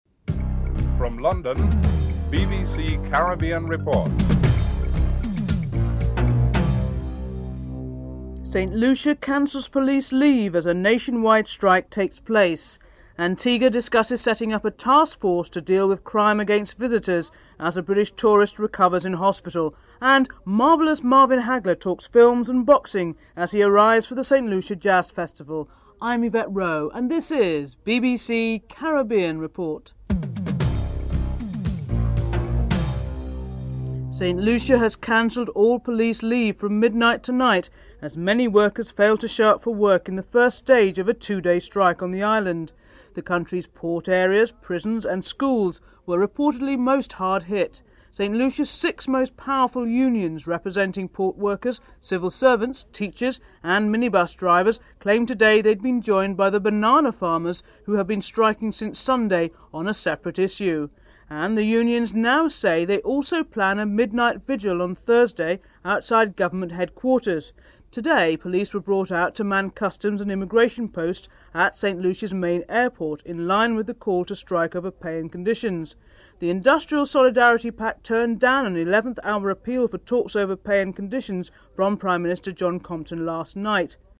7. Marvelous Marvin Hagler talks films and boxing as he arrives for the St. Lucia Jazz Festival (14:10-15:20)